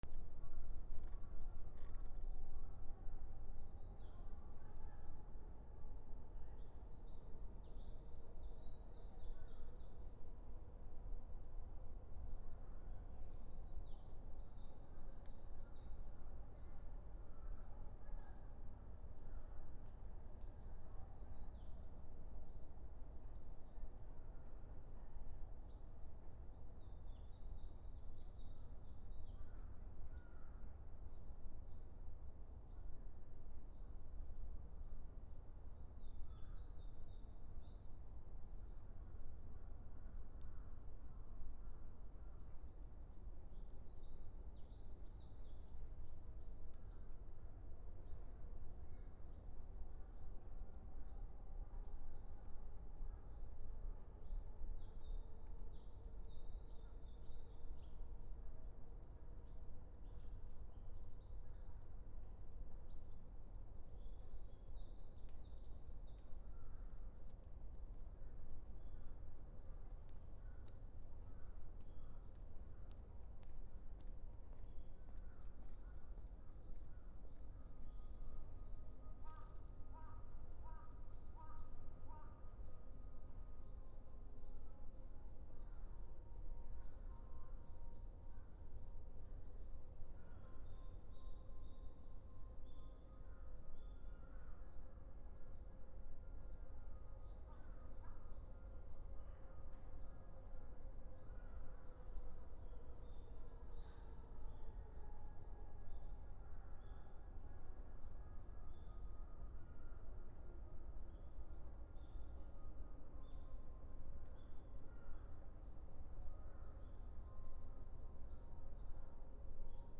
初夏 セミ
/ B｜環境音(自然) / B-25 ｜セミの鳴き声 / セミの鳴き声_70_初夏